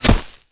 bullet_impact_grass_01.wav